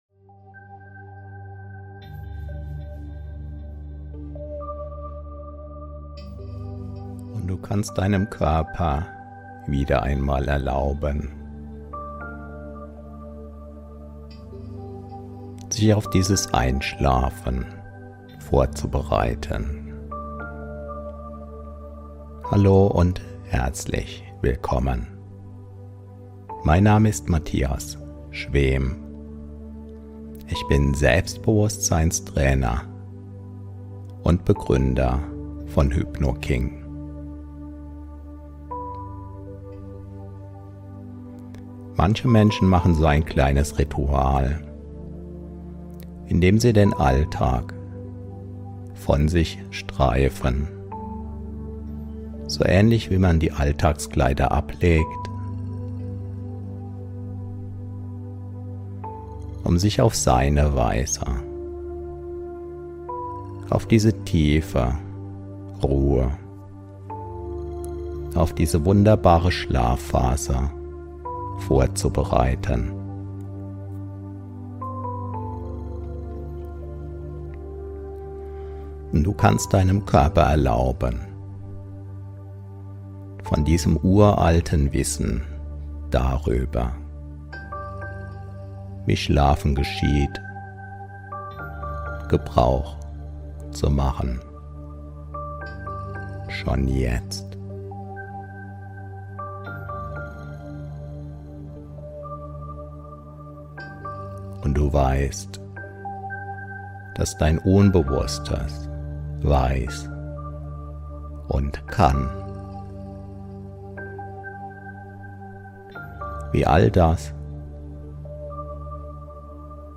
Diese Hypnose führt dich sanft in einen tiefen, erholsamen Schlaf.
In dieser einzigartigen Schlafhypnose erlebst du eine tiefe Entspannung und öffnest gleichzeitig die Tore zu deiner inneren Weisheit. Während du entspannst und einschläfst, werden positive Botschaften und Suggestionen an dein Unterbewusstsein weitergegeben.